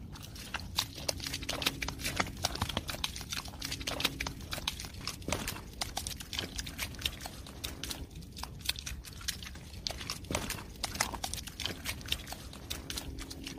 Tiếng Bước chân Chó đi trên Đất ướt, Đầm lầy….
Thể loại: Tiếng động
Description: Tiếng bước chân chó đi trên đất ướt, đầm lầy tạo nên âm thanh chân thực và sống động với những âm thanh đặc trưng như tiếng chân lún xuống bùn mềm, tiếng nước bắn tóe nhẹ nhàng hoặc sủi bọt, kèm theo tiếng lạch cạch khi móng chân va chạm đá nhỏ hay cành cây ẩm ướt.
tieng-buoc-chan-cho-di-tren-dat-uot-dam-lay-www_tiengdong_com.mp3